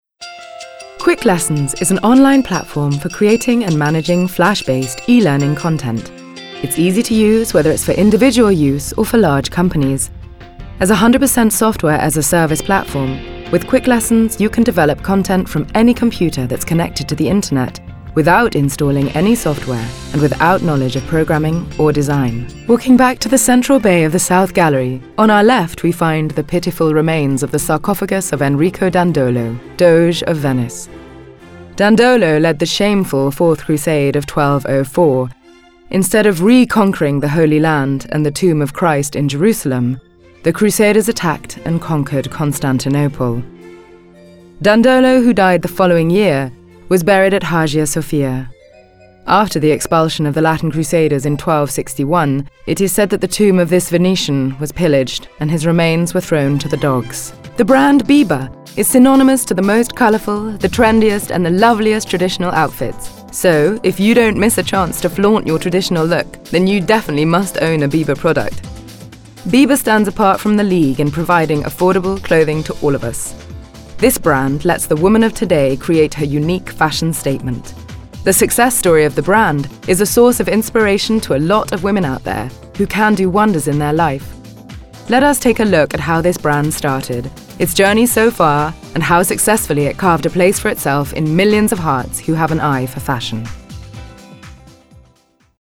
Anglais (Britannique)
Commerciale, Naturelle, Polyvalente, Profonde, Chaude
Corporate